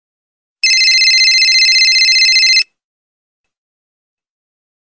P O L I C E 00:25 < P O L I C E Sound Effect Message 00:05 < Sound Effect Message Alarm Tone 02 00:26 < Alarm Tone 02 wistle 00:02 < wistle Extreme Alarm Clock 00:30 < Extreme Alarm Clock SHOW ALL SIMILAR